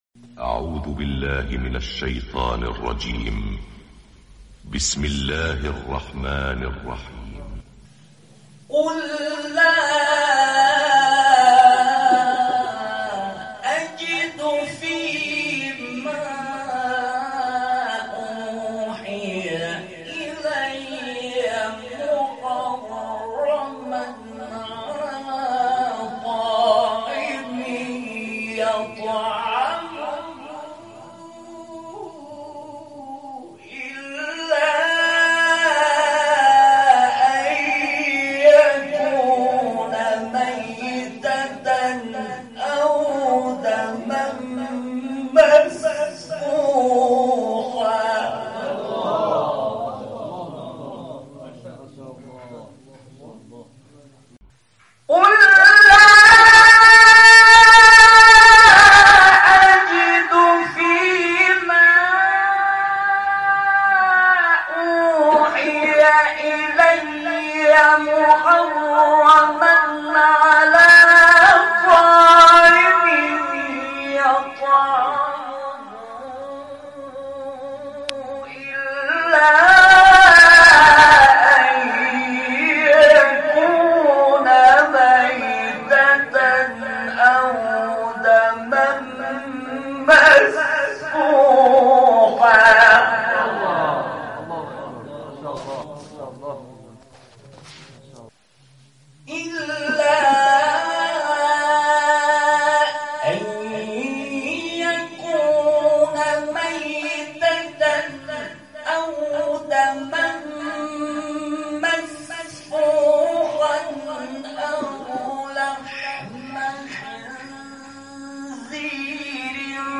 گروه شبکه اجتماعی: مقاطعی از تلاوت‌های صوتی قاریان برجسته کشور ارائه می‌شود.